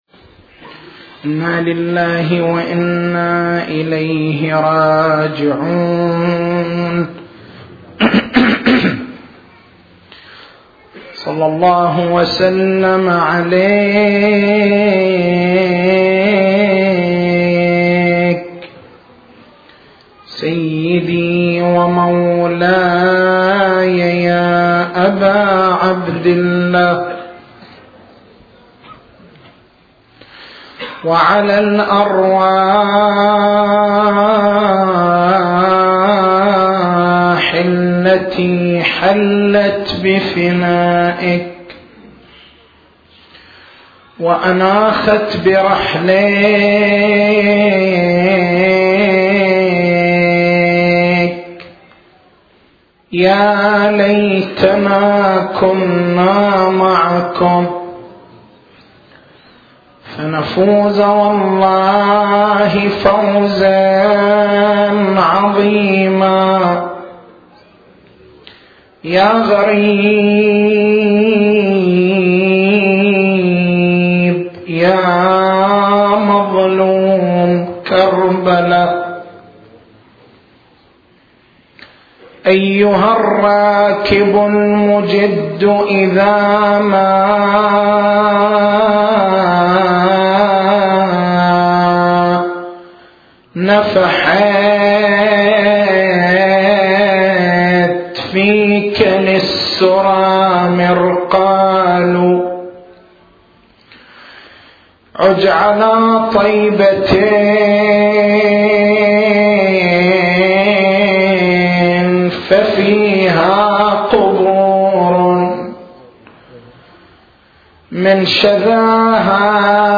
تاريخ المحاضرة: 12/01/1428